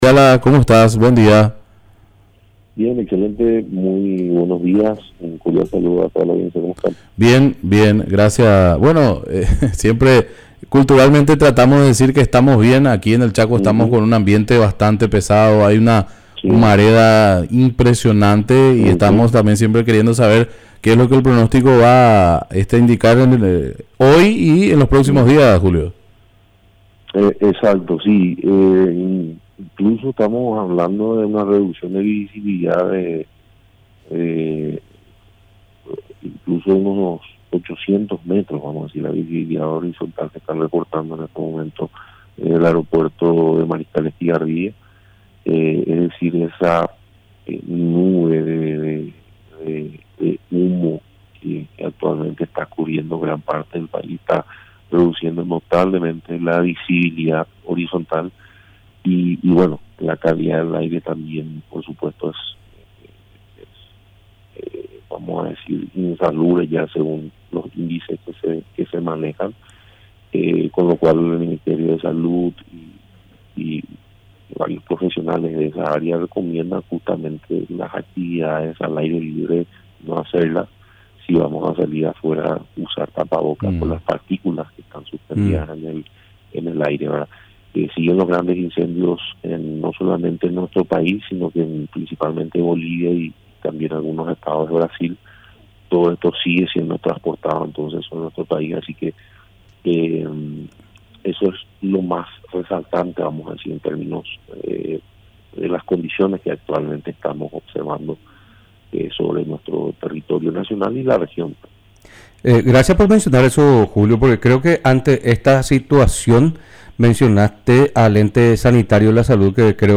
Entrevistas / Matinal 610 Informe meteorológicos e hidrológicos Sep 10 2024 | 00:14:46 1x 00:00 / 00:14:46 Subscribe Share RSS Feed Share Link Embed